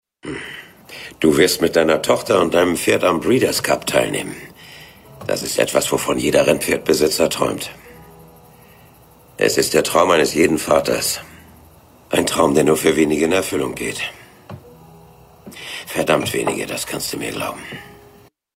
Seine markante, warme Stimme lieh er internationalen Stars wie Burt Reynolds, Kris Kristofferson und Peter Fonda.
Besonders seine ruhige, vertrauensvolle Sprechweise machte ihn zu einer idealen Besetzung für Erzählerrollen.
Synchronausschnitt: Kris Kristofferson (als Pop Crane) in Dreamer – Ein Traum wird wahr (2005)